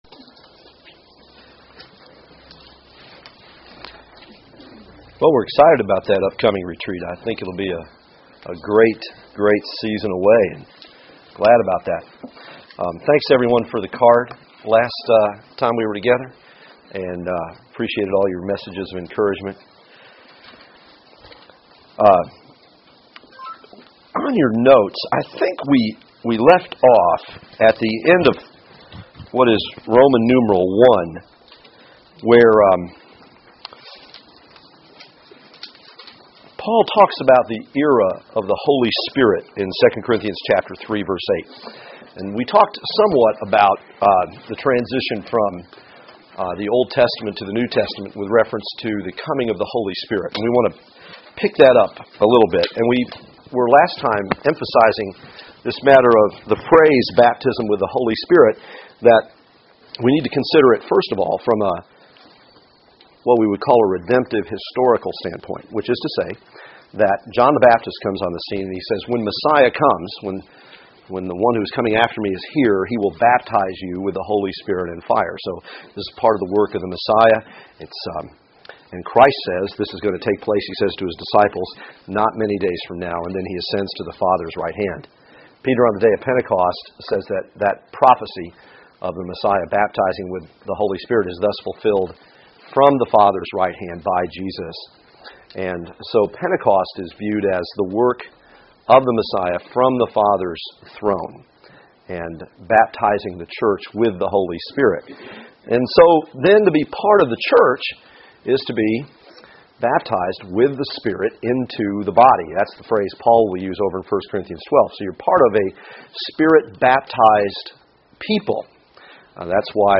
Service Type: Women's Bible Study